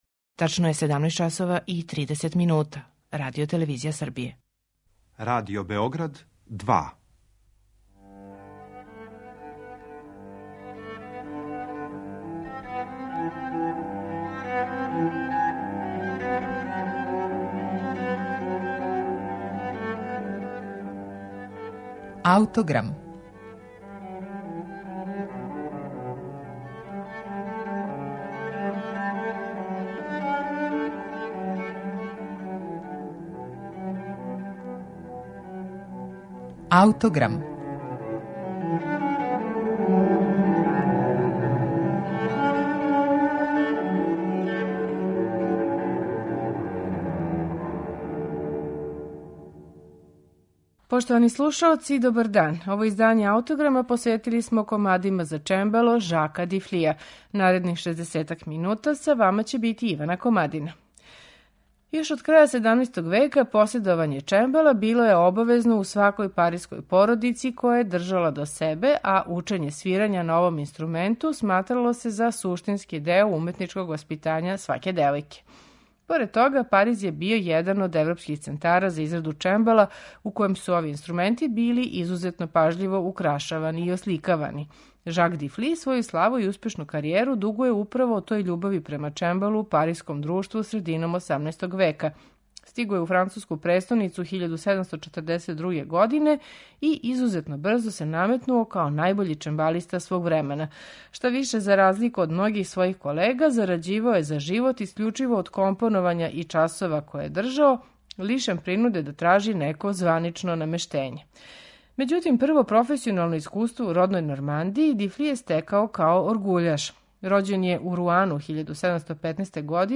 Чембалиста Жак Дифли
у дворани дворца д'Асе поред Монпељеа
чембалисткиња
на чембалу из 18. века